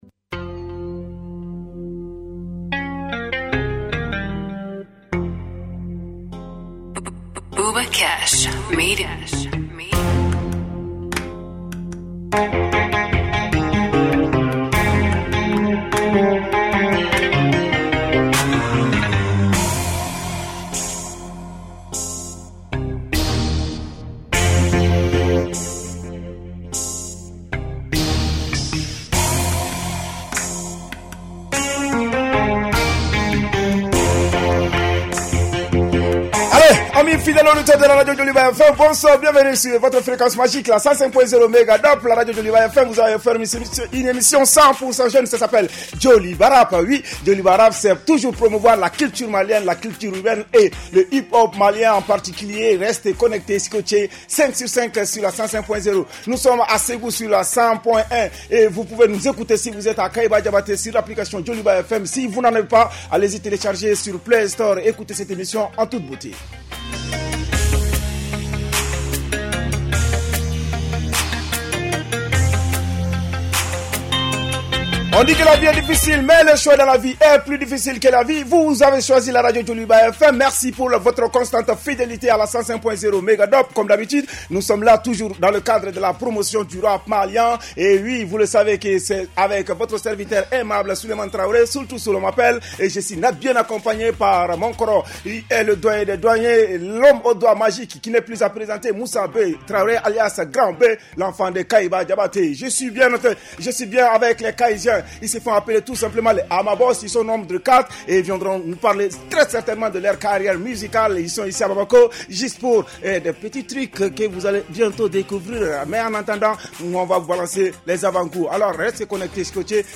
Un programme 100 % dédié à la scène rap et hip-hop du Mali avec des interviews exclusives, des freestyles et toute l’actualité croustillante de vos rappeurs préférés.